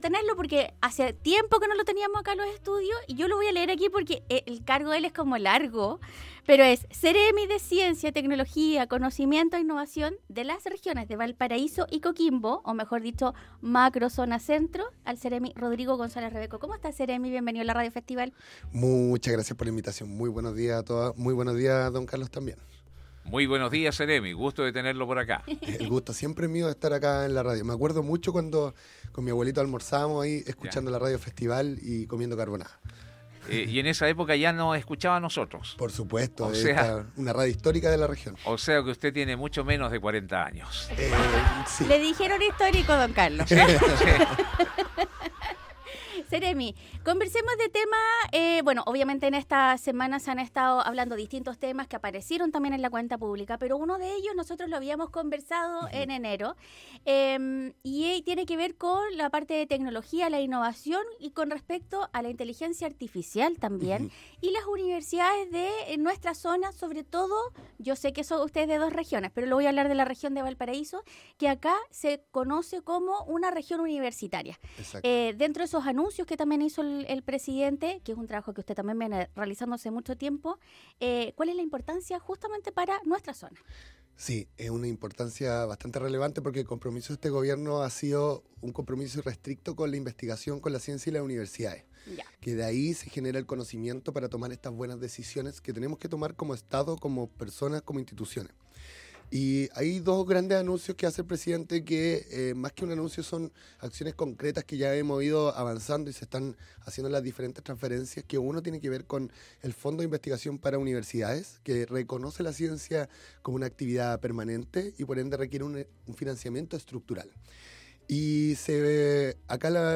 El Seremi de Ciencia, Tecnología, Conocimiento e Innovación de las Regiones de Valparaíso y Coquimbo, Rodrigo Gonzalez Rebeco, estuvo en los estudios para contar detalles de este nuevo super centro de IA que se instalará en Viña del Mar